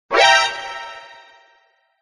Категория: SMS рингтоны